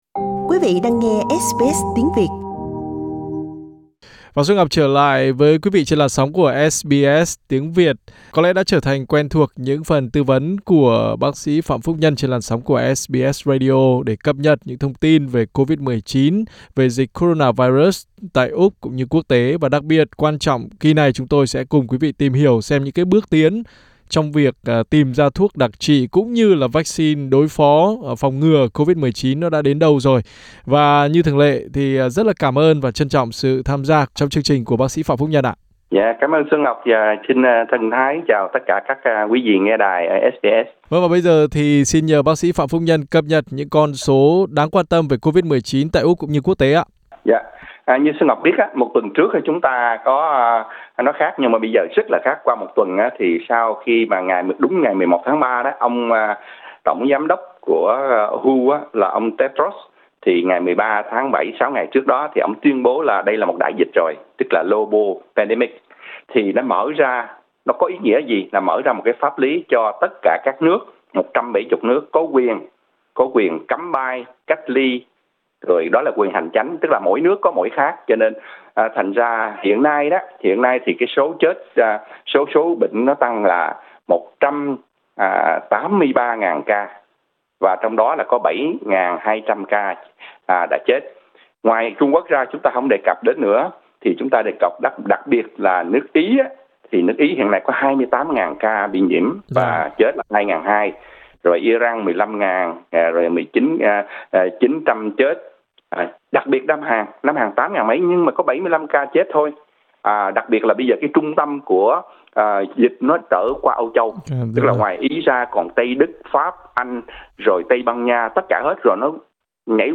Highlights Hơn 400 ca nhiễm COVID-19 tại Úc, tăng gấp đôi sau một tuần lễ.